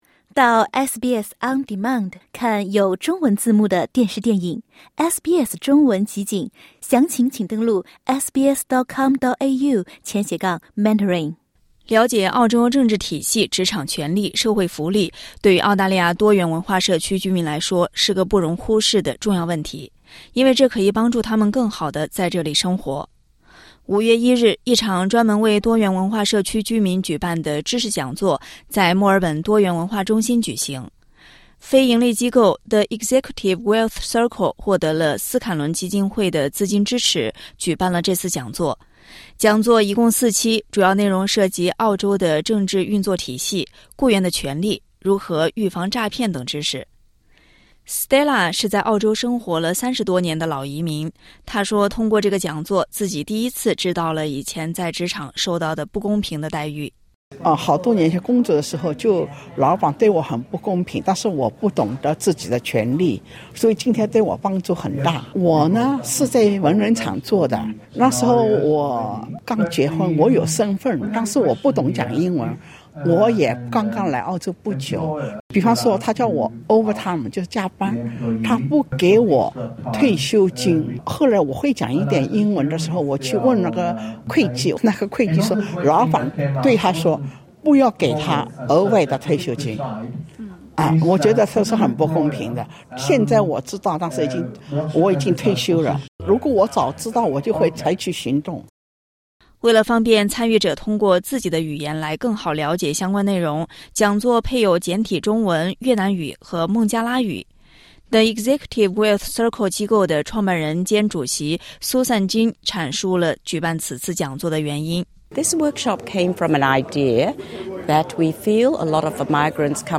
点击上方音频收听完整采访报道。